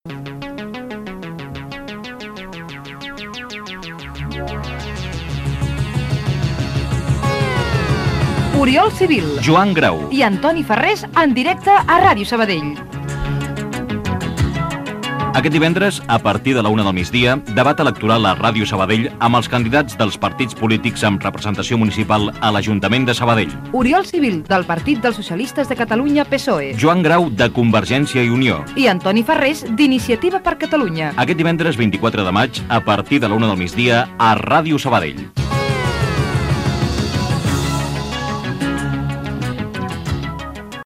Promoció del debat entre els candidats a l'alcaldia de Sabadell de les eleccions municipals de 1991